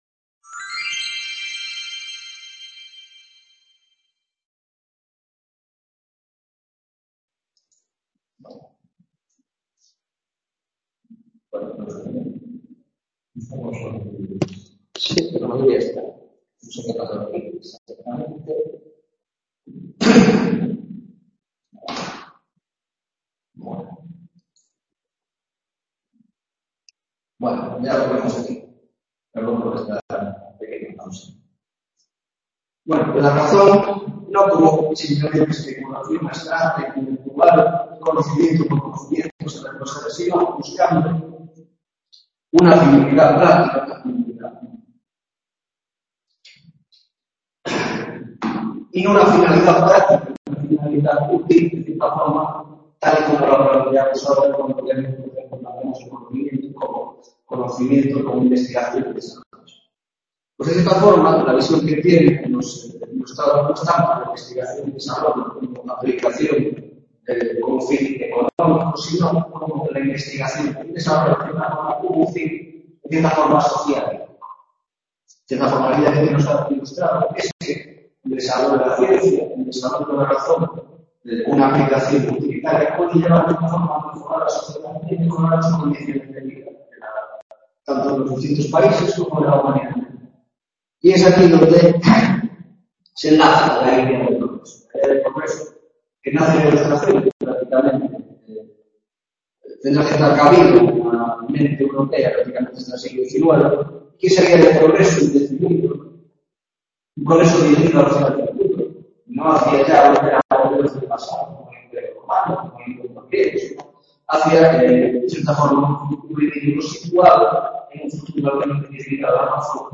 8ª tutoria de Historia de la Baja Edad Moderna - Ilustración, parte 2ª -Aviso: el vídeo esta dividido en dos partes al haberse interrumpido por causas ajenas a nuestra voluntad la grabación durante la tutoría